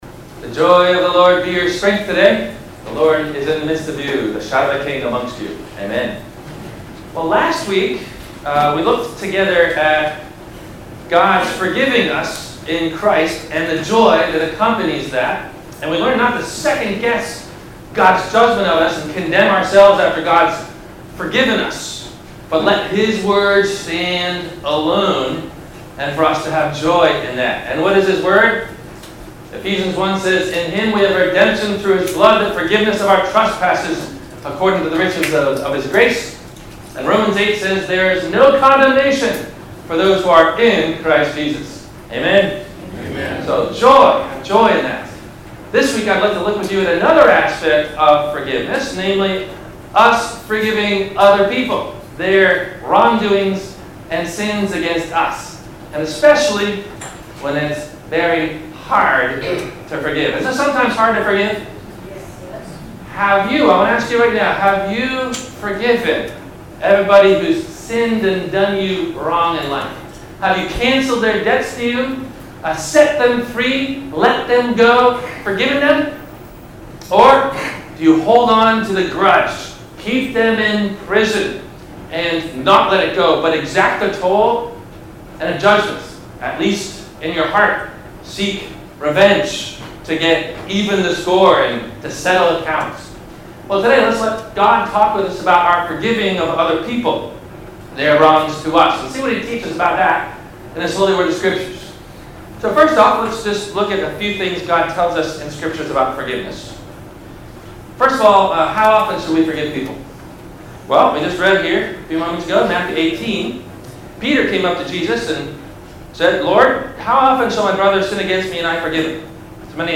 How To Forgive When it’s Hard – WMIE Radio Sermon – February 26 2018